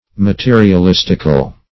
\Ma*te`ri*al*is"tic*al\, a.